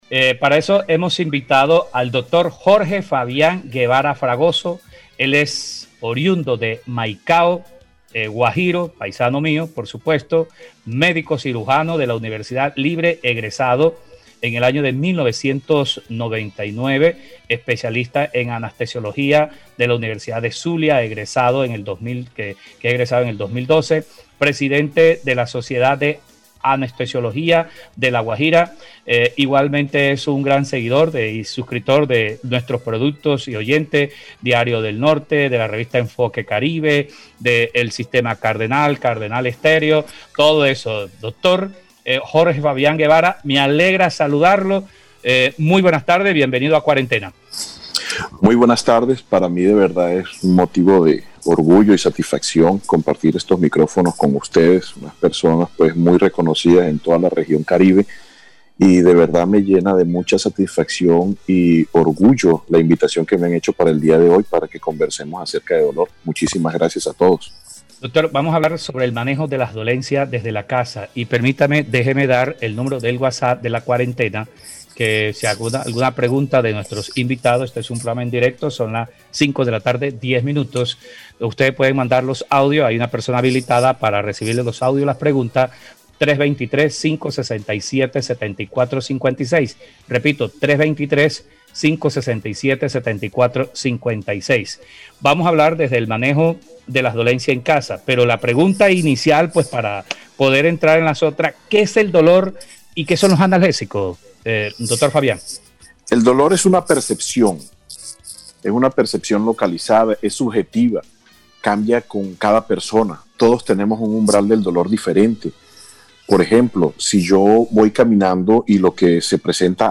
quien en comunicación con el programa Cuarentena del Sistema Cardenal, dio algunas indicaciones sobre el manejo de las dolencias desde la casa.